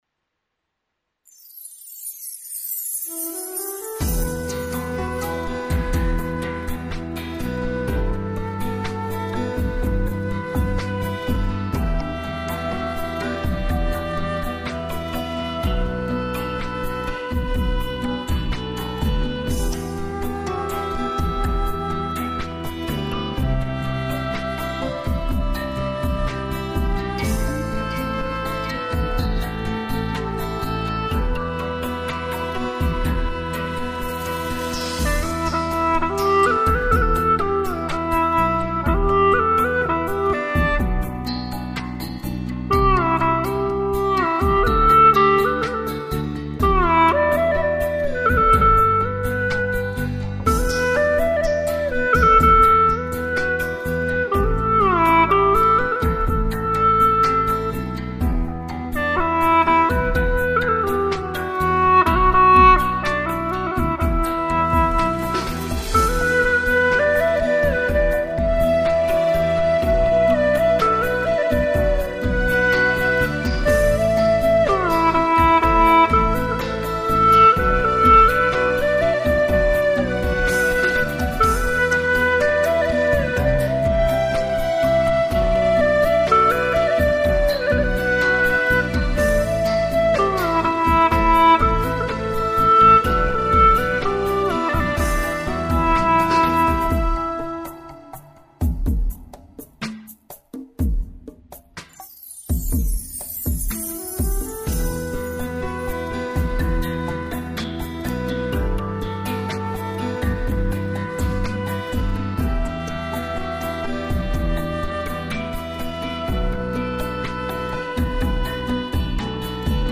调式 : G 曲类 : 民族